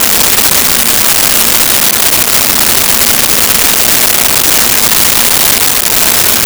Vacuum Cleaner Loop 01
Vacuum Cleaner Loop 01.wav